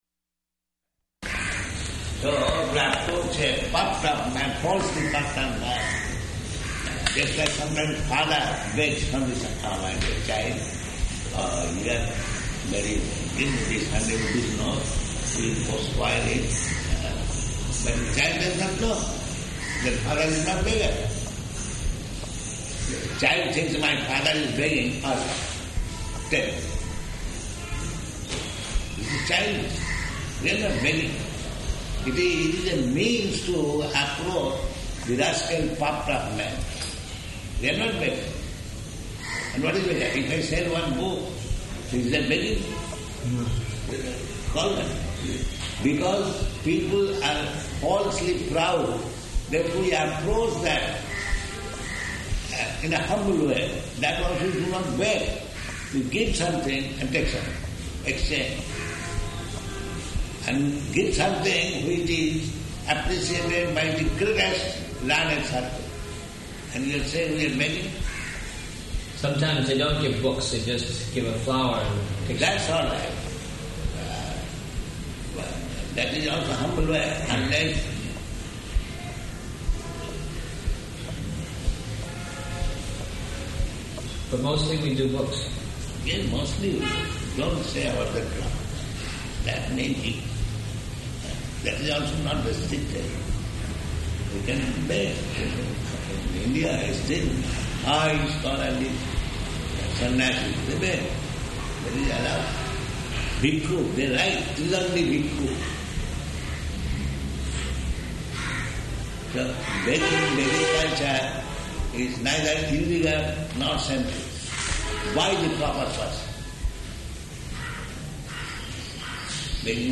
Room Conversation
Location: Calcutta